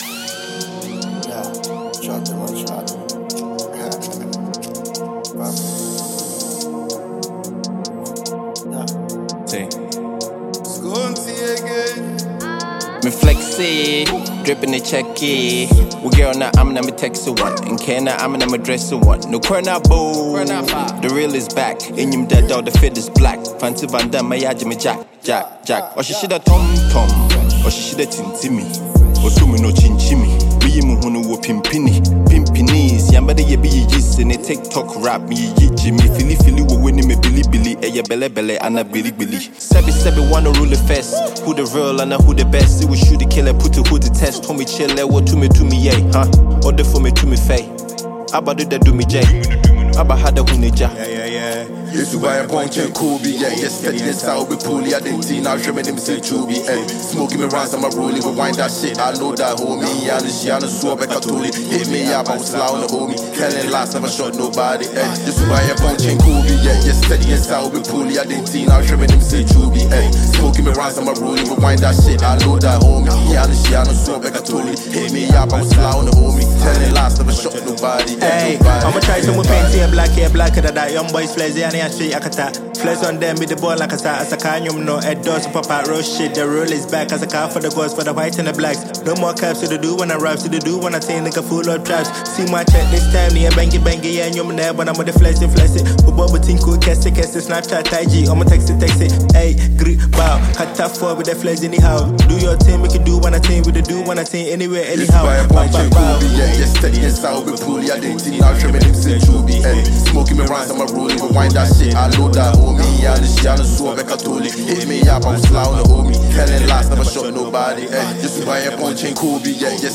Ghana Music Music
Asakaa drillers